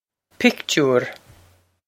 pictiúr pick-toor
Pronunciation for how to say
This is an approximate phonetic pronunciation of the phrase.